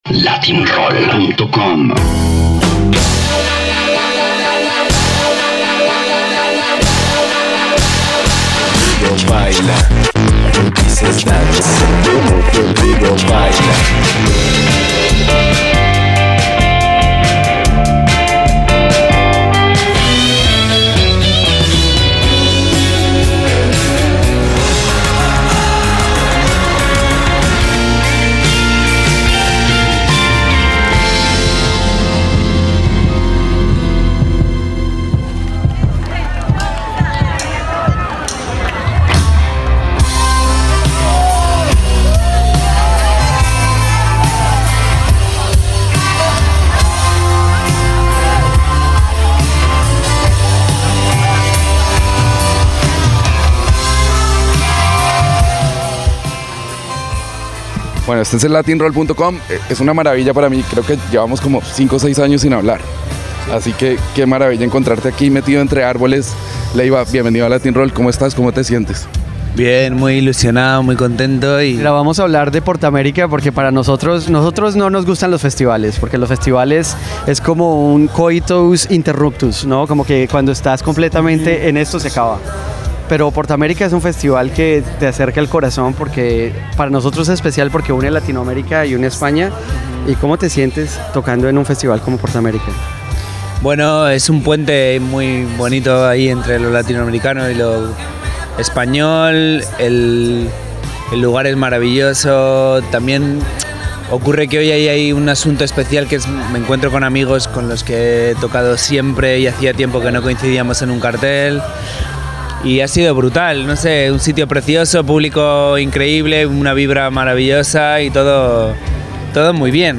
Entrevista con uno de los guitarristas y compositores mas importantes de la actualidad española durante su gira de verano.
Leiva_PortAmerica_2017.mp3